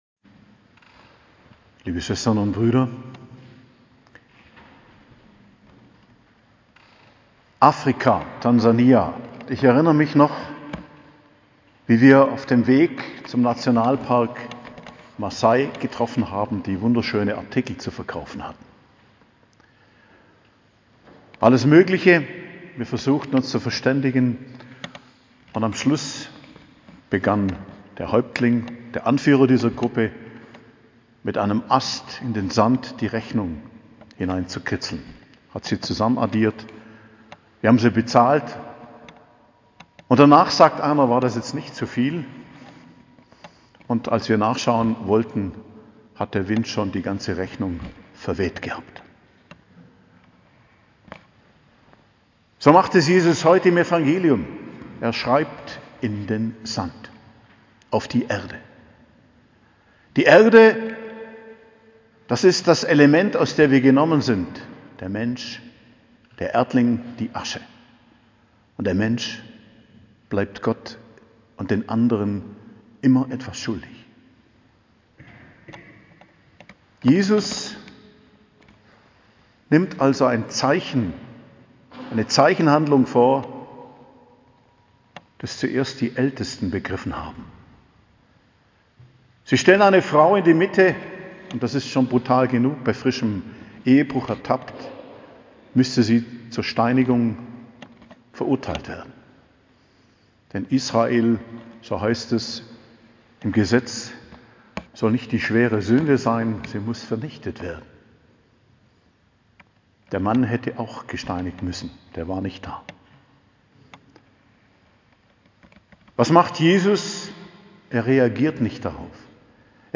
Predigt zum 5. Fastensonntag am 3.04.2022